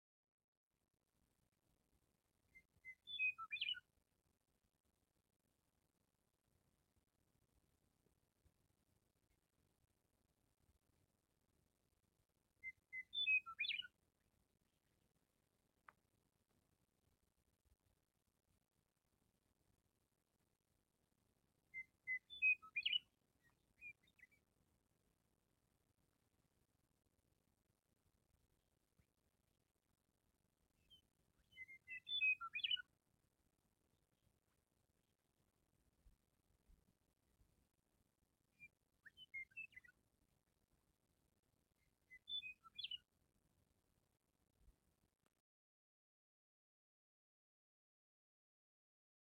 Song Of The Meadow Lark
They flit from sagebrush to sagebrush, varying their songs, speaking to each other and to us.
No human voice can match their whistles and warbles, their trills and scales.
MeadowLark.mp3.mp3